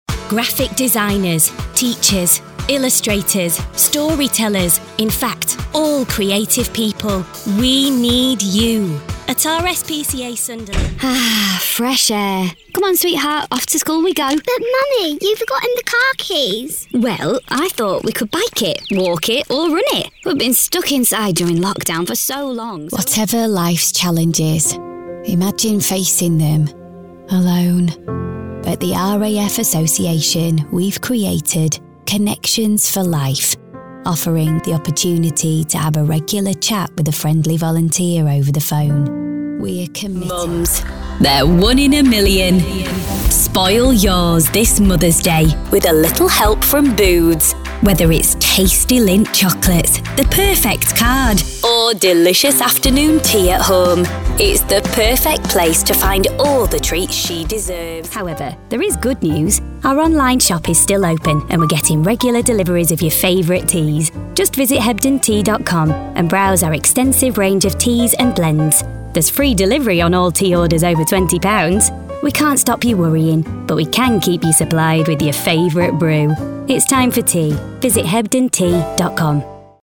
Radio Showreel
Her conversational tone and acting training provide a wide variety of styles, from straight-laced narration to bubbly commercials.
Female
Yorkshire
Bright
Friendly
Confident